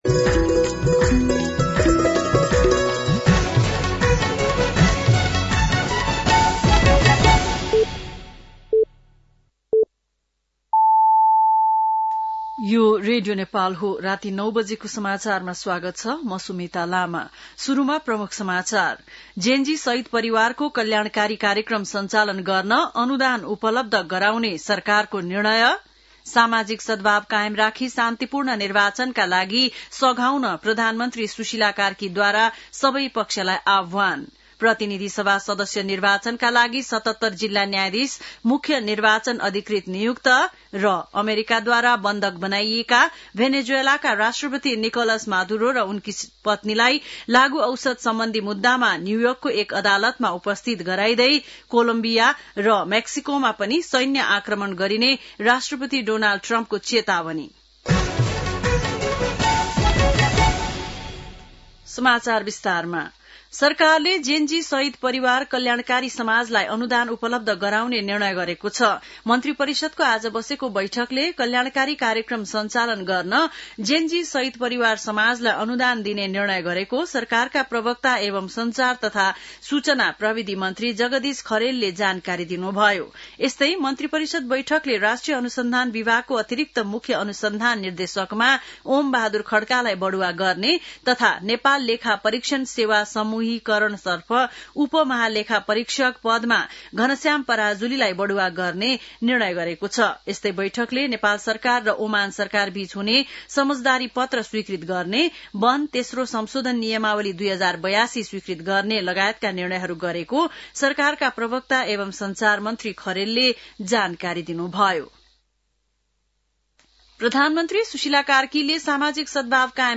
बेलुकी ९ बजेको नेपाली समाचार : २१ पुष , २०८२
9-PM-Nepali-NEWS-9-21.mp3